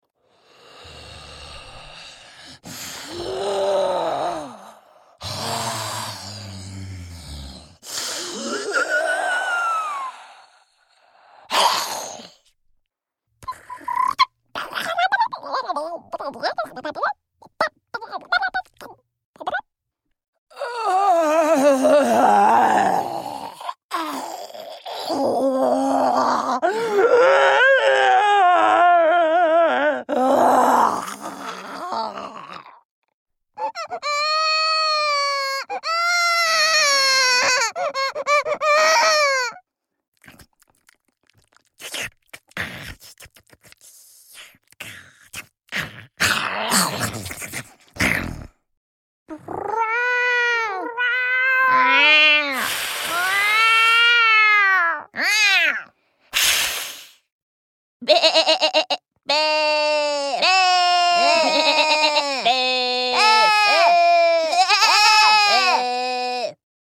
Voice demos
Versatile and flexible mezzo vocalist.